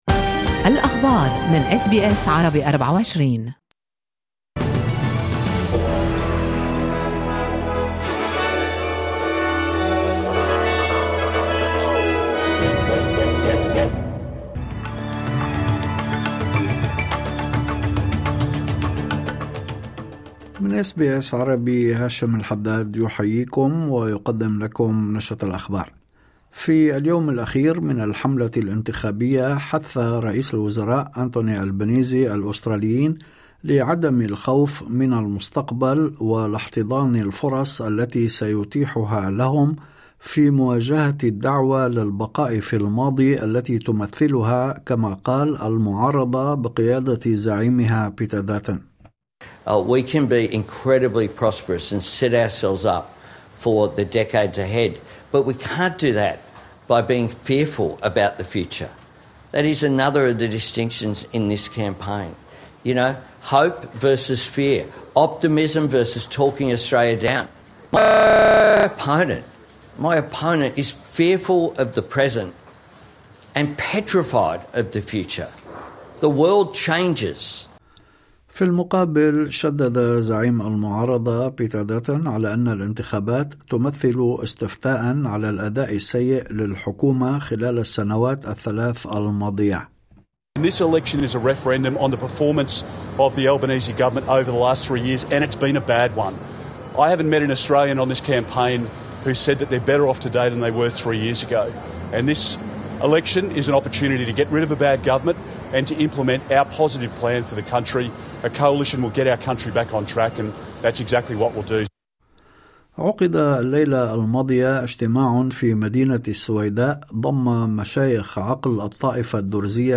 نشرة أخبار الظهيرة 02/05/2025